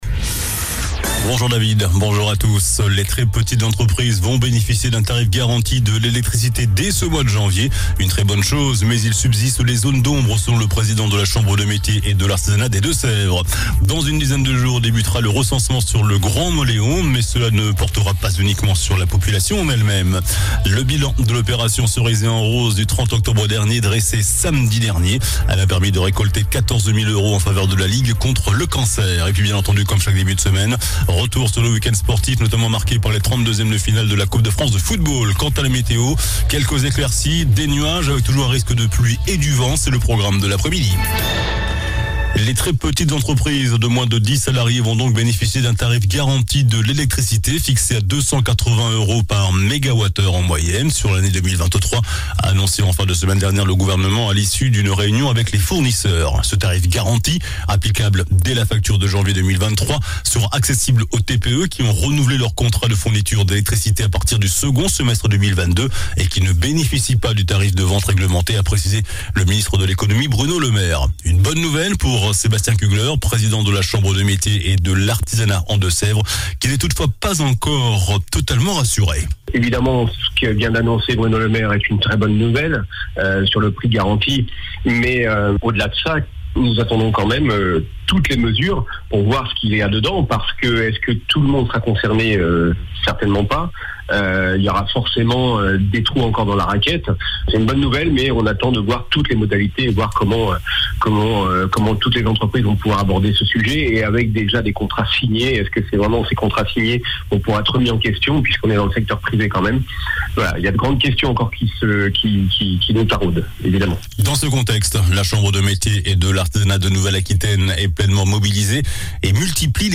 JOURNAL DU LUNDI 09 JANVIER ( MIDI )